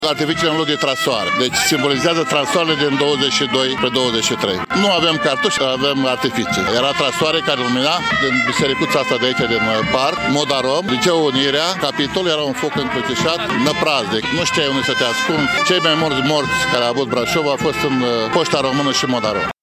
Aproximativ 70 de persoane au participat la marșul eroilor Revoluției, care a pornit din Piața Sfatului și a coborât spre Modarom.
Participanții au avut în mâini făclii, iar coloana a fost condusă, pentru prima dată, de o fanfară.
Aceasta a intonat Imnul de Stat al României, cel care se auzea și în decembrie 89:
AMBIANTA-IMN.mp3